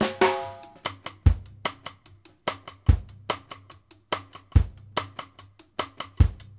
Index of /90_sSampleCDs/Sampleheads - New York City Drumworks VOL-1/Partition F/SP REGGAE 72